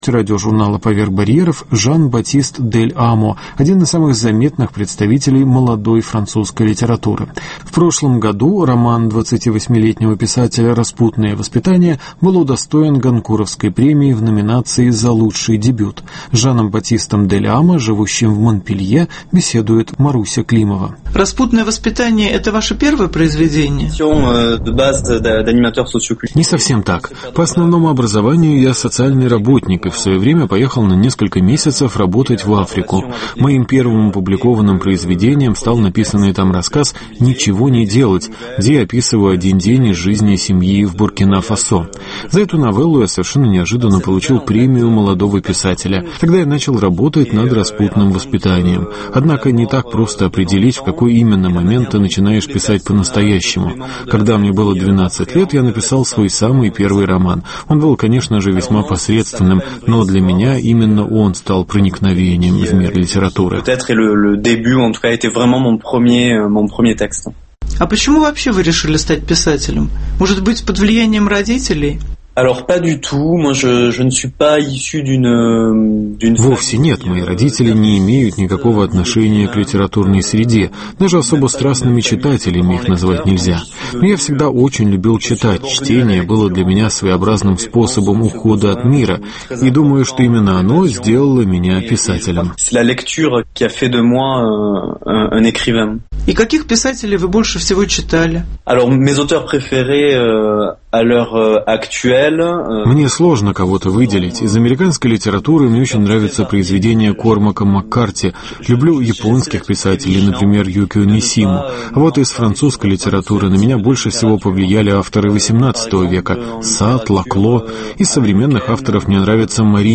Беседа с лауреатом Гонкуровской премии Жаном-Батистом Дель Амо